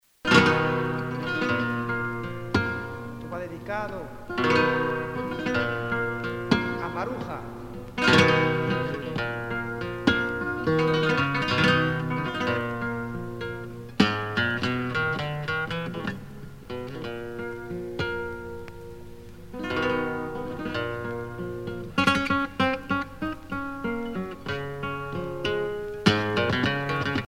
danse : séguédille
Pièce musicale éditée